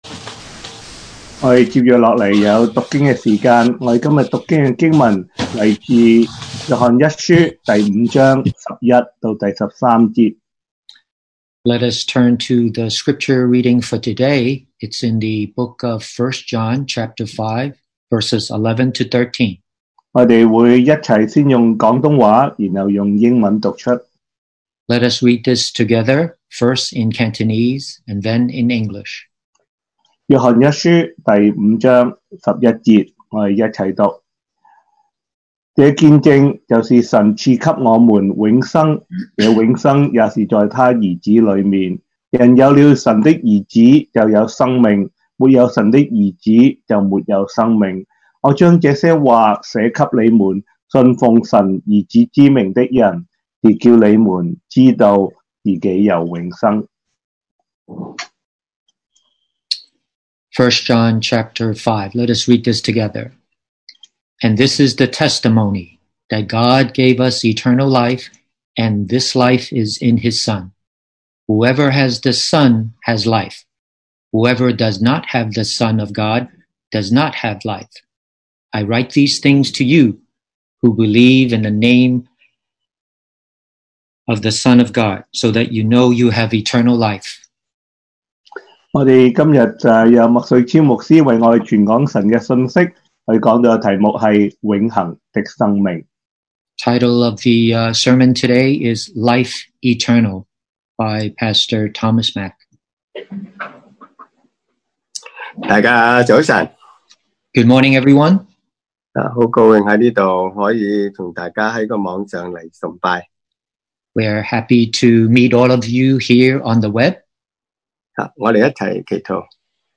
2020 sermon audios
Service Type: Sunday Morning